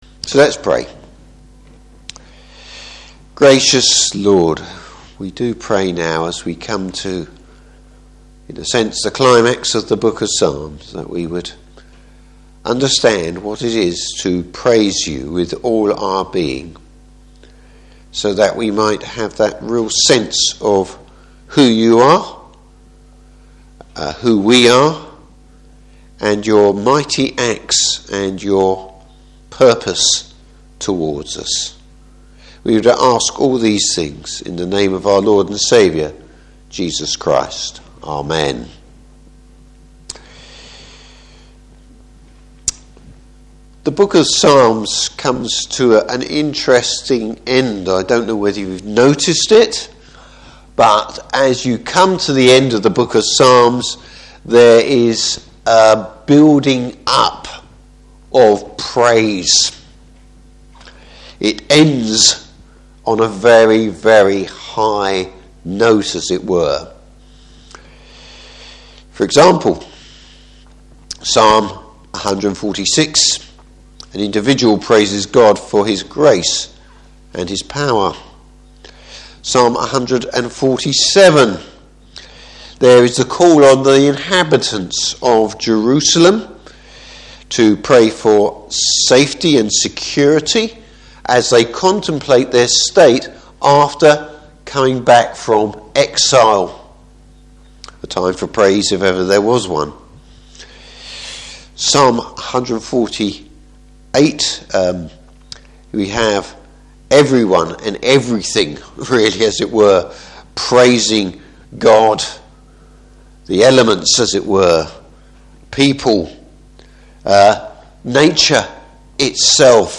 Passage: Psalm 150. Service Type: Evening Service The how and whys of worship.